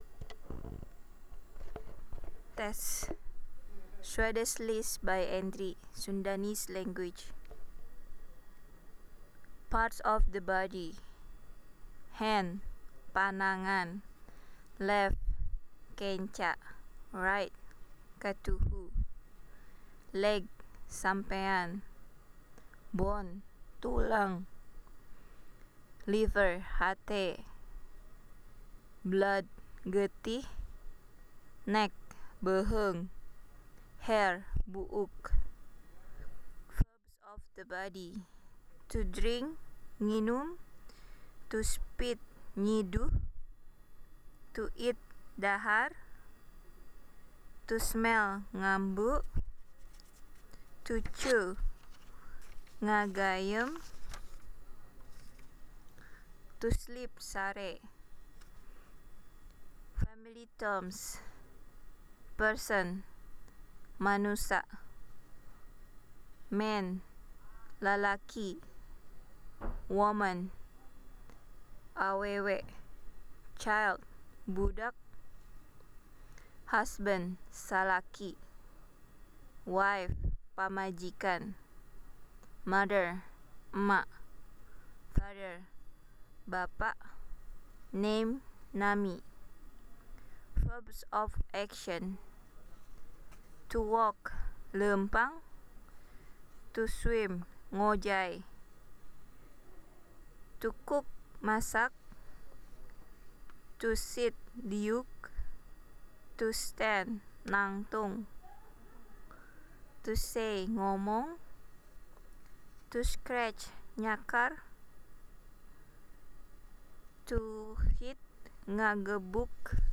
Wordlist/Swadesh
English Sunda-Bogor International Phonetic Alphabet (IPA)
SwadeshList.wav